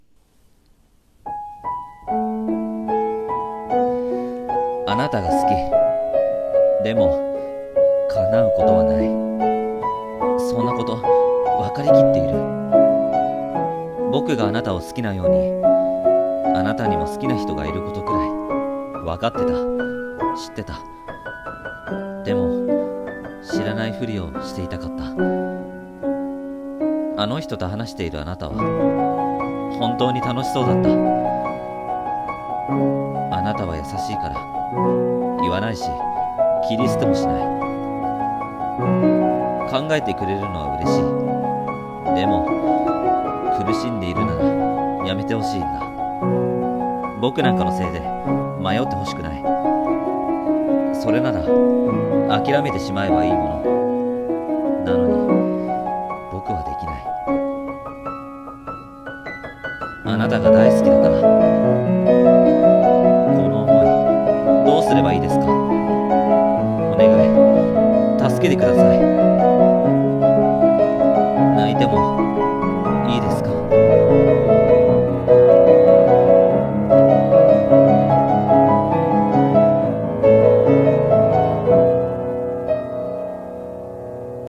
【声劇】泣いてもいいですか